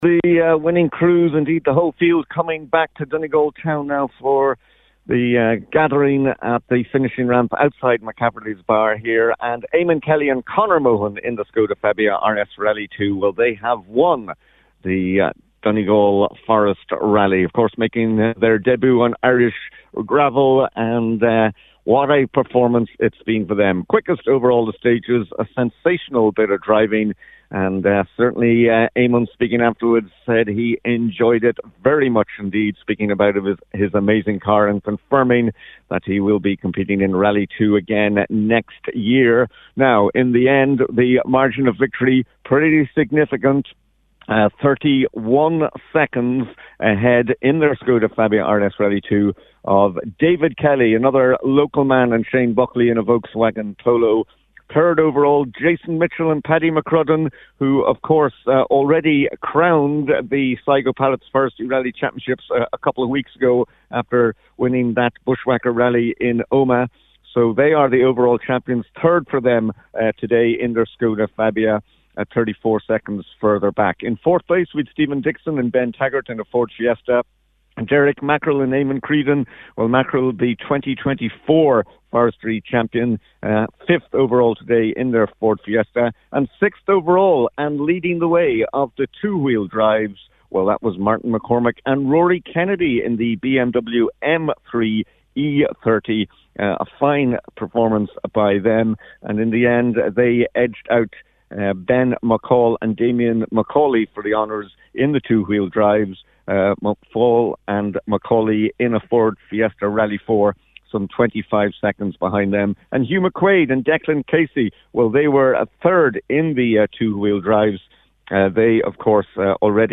Full report